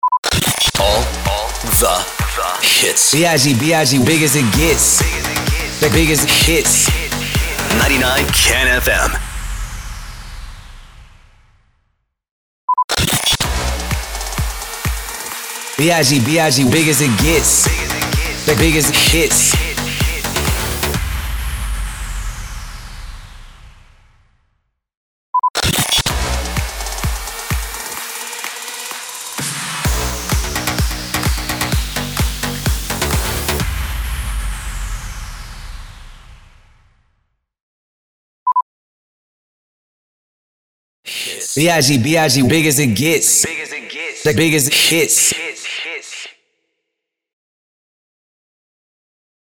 639 – SWEEPER – BIGGEST HITS